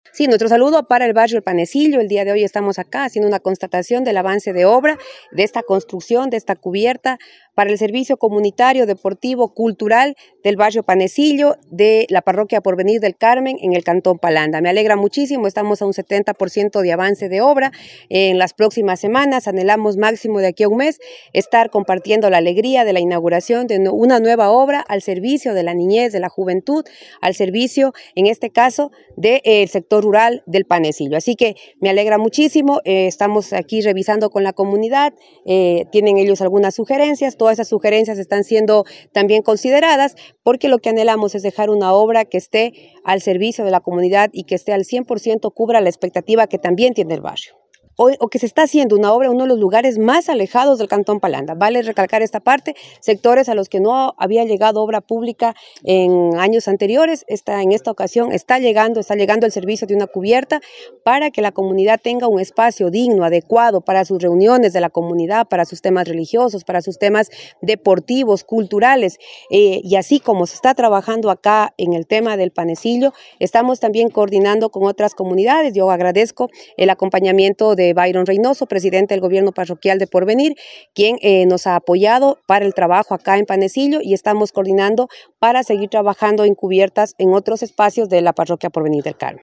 KARLA REÁTEGUI, PREFECTA
KARLA-REATEGUI-PREFECTA-4.mp3